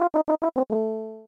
game_over.mp3